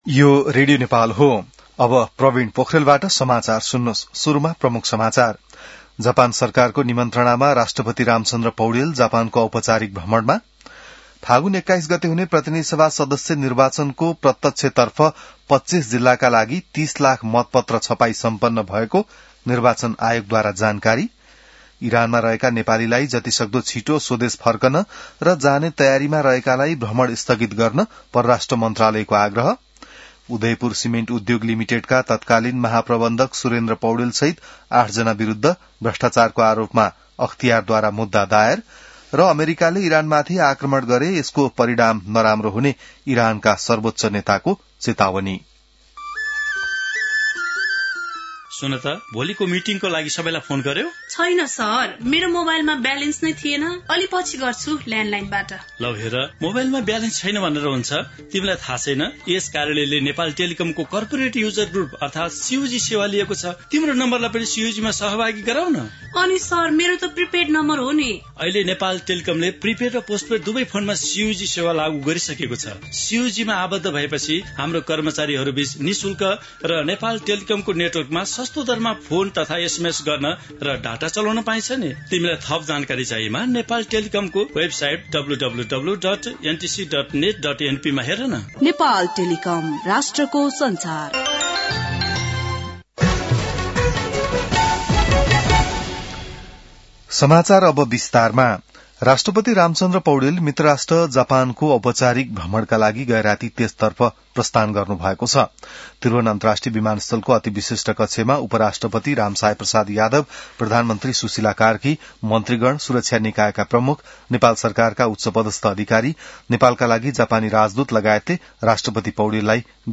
बिहान ७ बजेको नेपाली समाचार : १९ माघ , २०८२